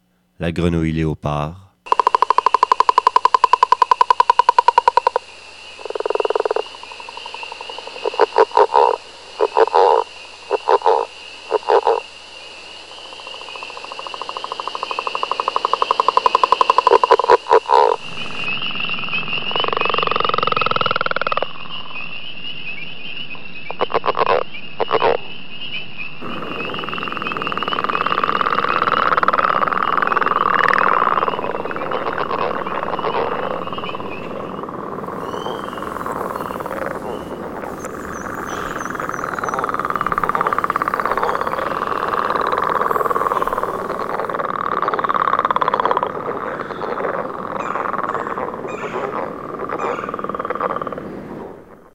Grenouille Léopard
grenouille-leopard.mp3